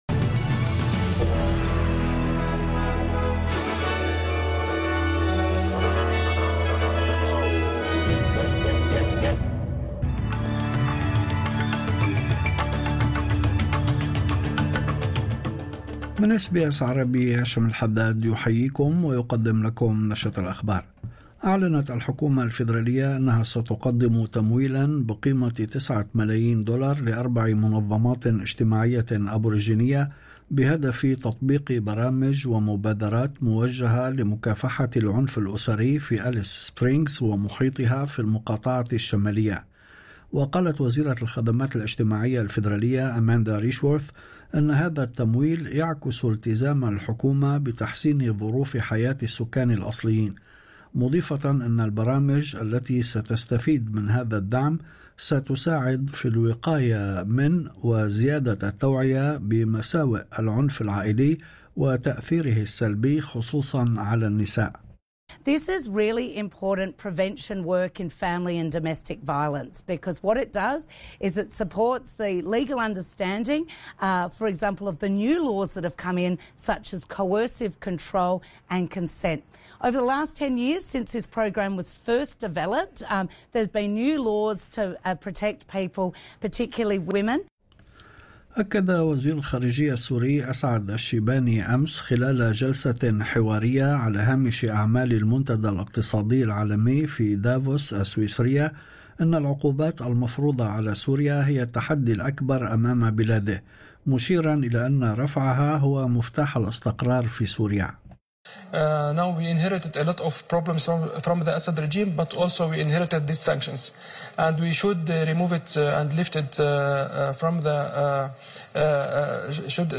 نشرة أخبار الظهيرة 23/01/2025